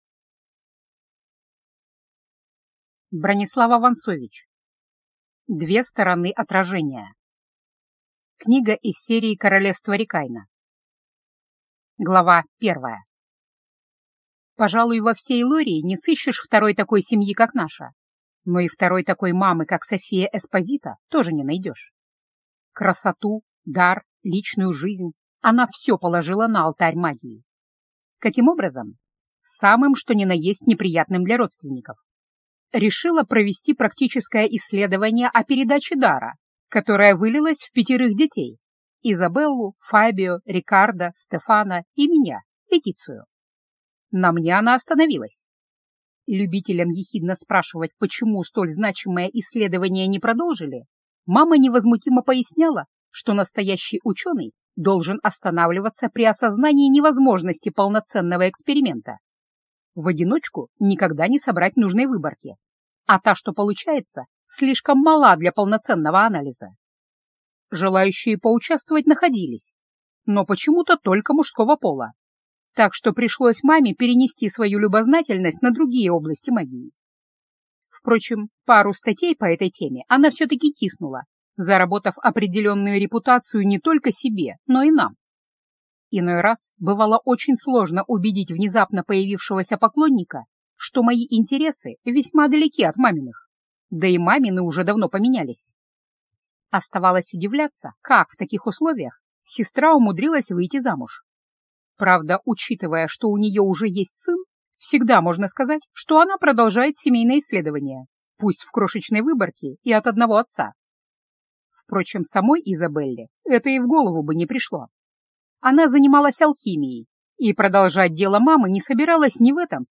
Аудиокнига Две стороны отражения | Библиотека аудиокниг